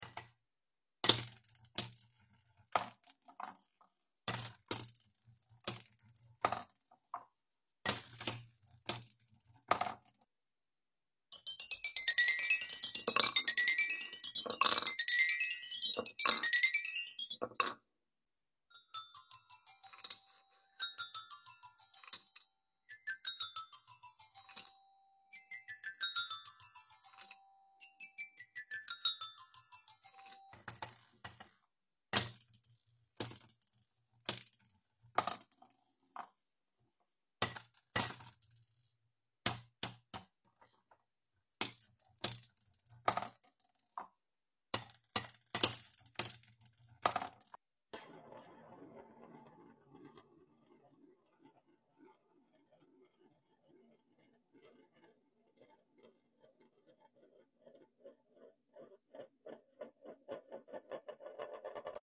Hammering Fun – Wooden Ball Sound Effects Free Download